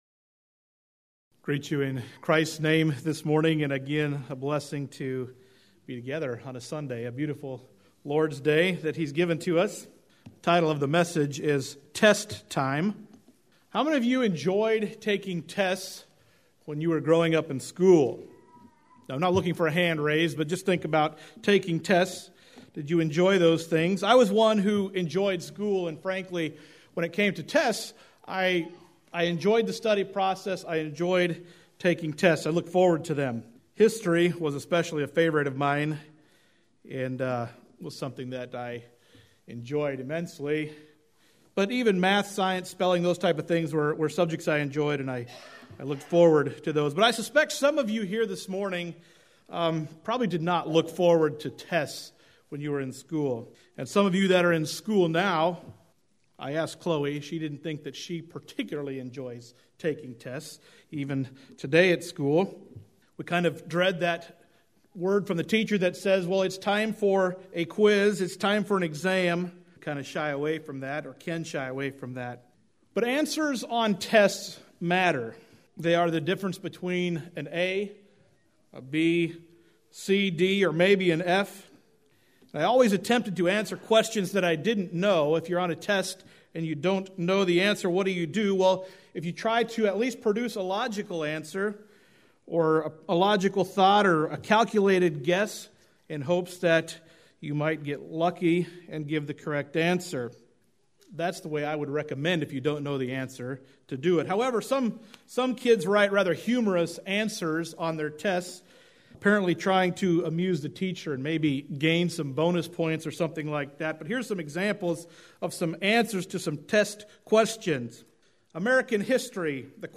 Sermons - Living Water Mennonite Church